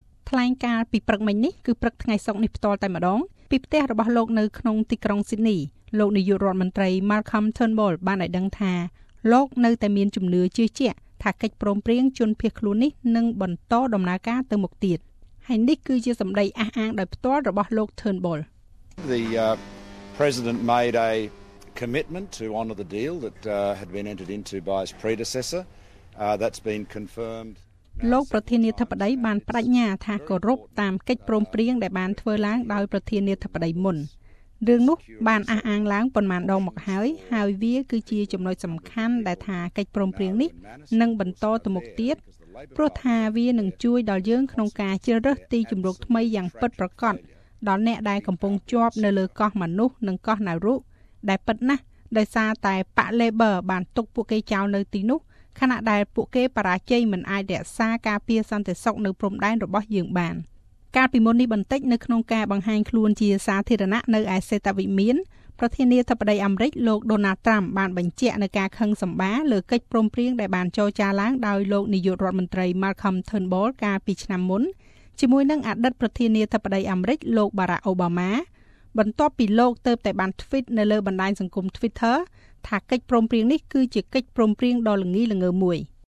Speaking this morning (fri)from his home in Sydney Prime Minister Malcolm Turnbull said he remains confident the deal will go ahead.